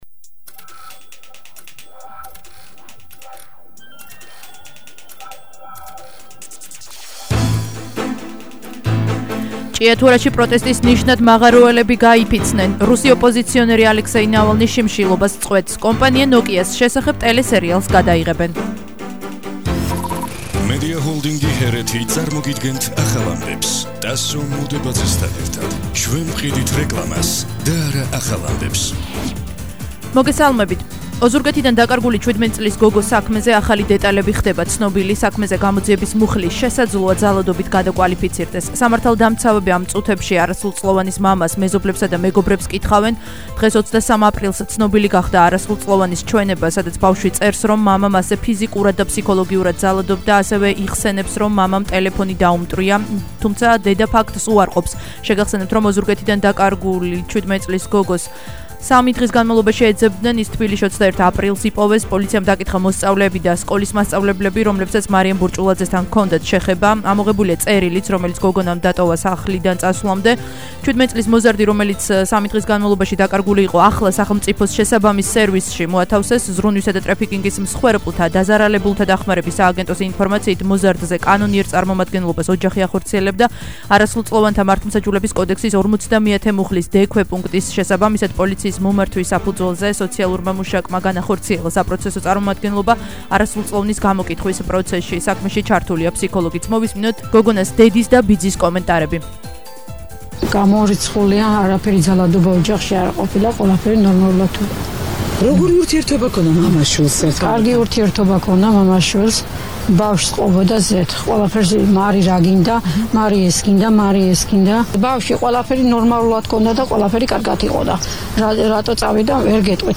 ახალი ამბები 17:00 საათზე –23/04/21 - HeretiFM